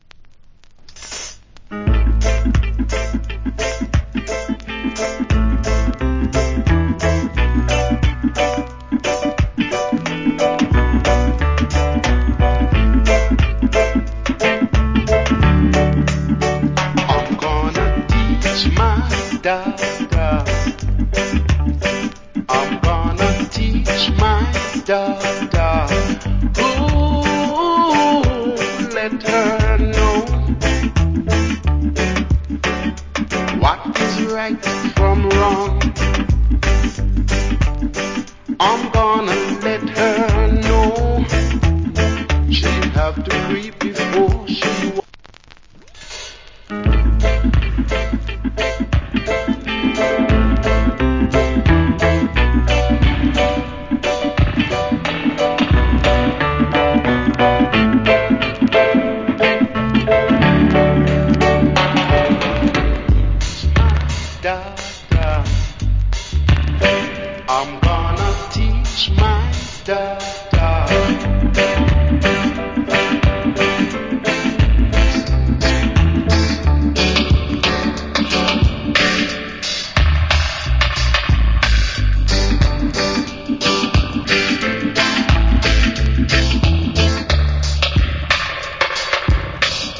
Nice Vocal. Self Cover.